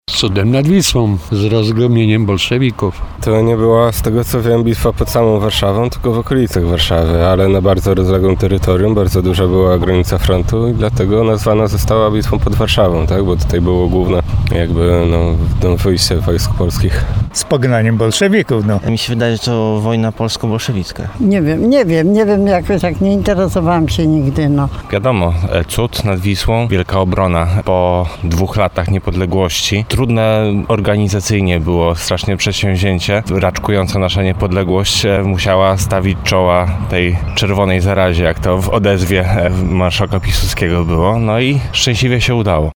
Zapytaliśmy warszawiaków z czym kojarzy im się bitwa z 1920 roku:
sonda.mp3